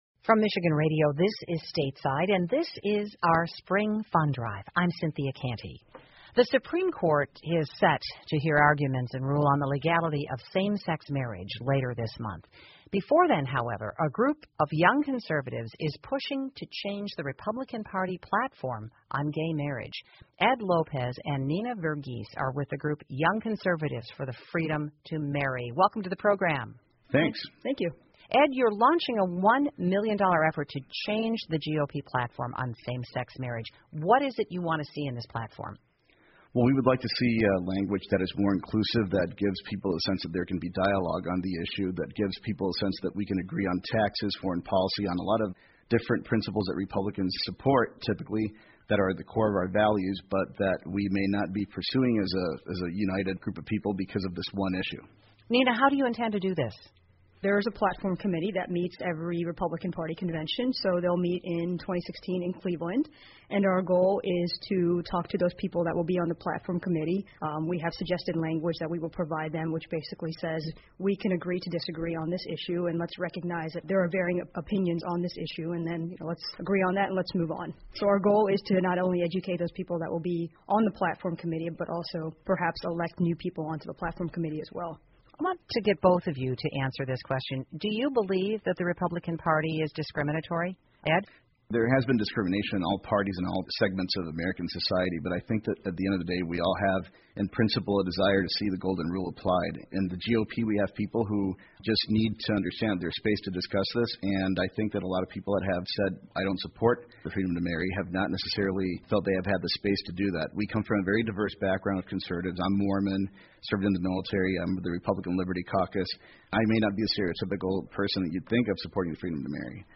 密歇根新闻广播 年轻人正为改变共和党对于同性婚姻的看法而努力 听力文件下载—在线英语听力室